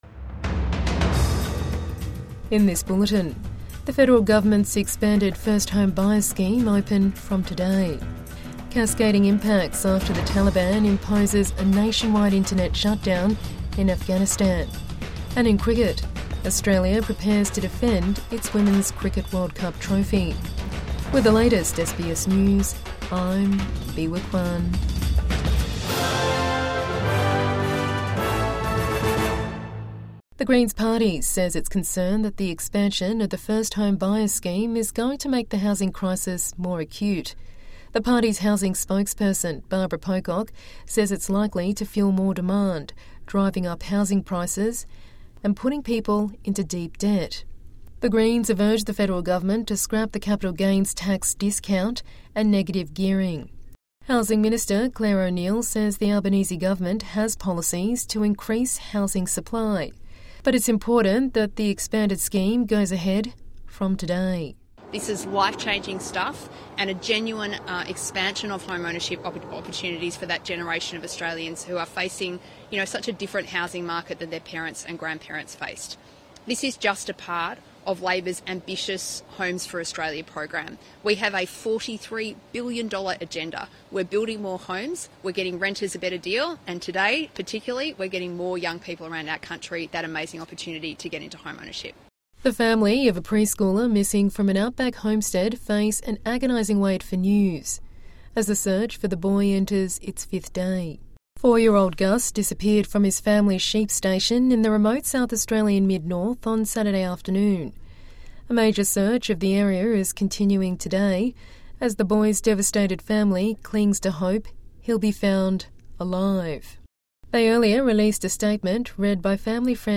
Government's first home buyers scheme expanded from today | Midday News Bulletin 1 October 2025